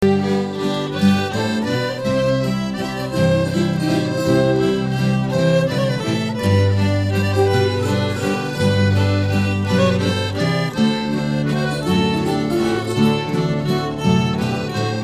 Algumas cantigas do Açores
Gentilmente cedidas pelo excelente "Grupo de Cantares Belaurora" de São Miguel, Açores, Pt.